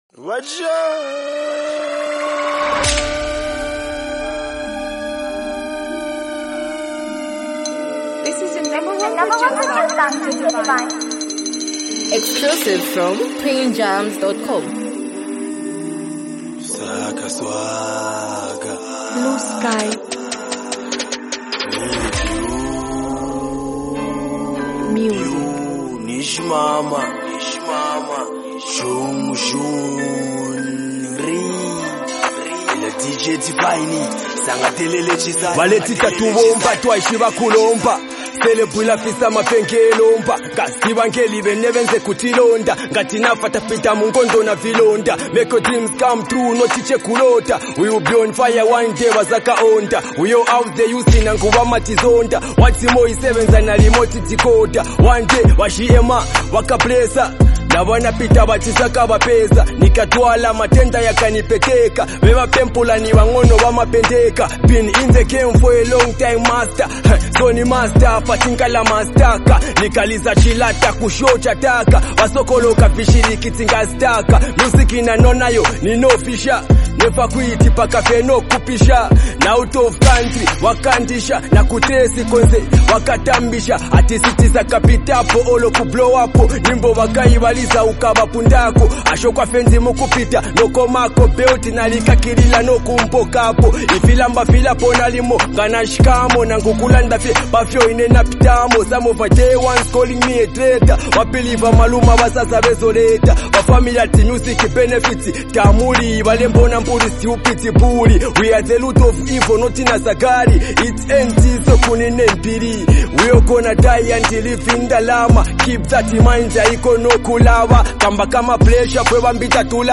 Highly talented act and super creative singer